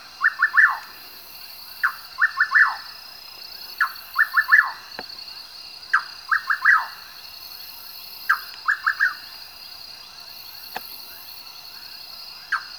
Atajacaminos Colorado (Antrostomus rufus)
Nombre en inglés: Rufous Nightjar
Localidad o área protegida: Paso de la Patria
Condición: Silvestre
Certeza: Vocalización Grabada